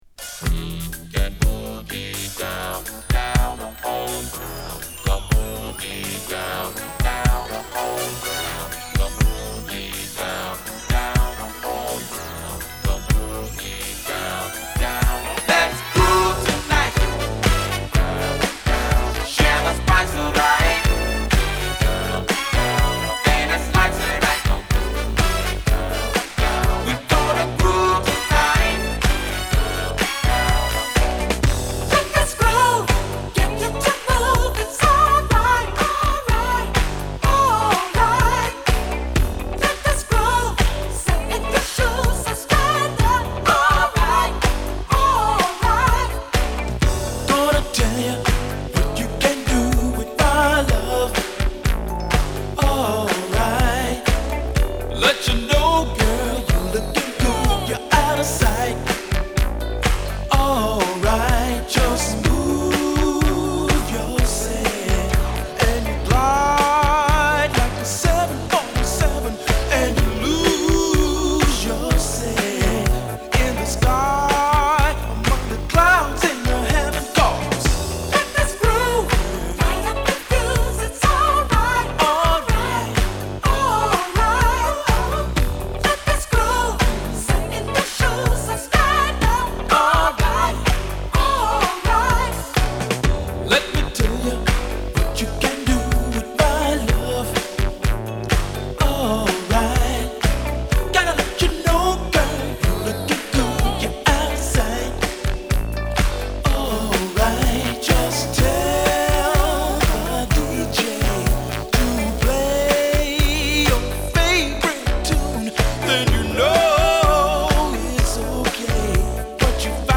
説明不要の文句なしダンス・クラシック！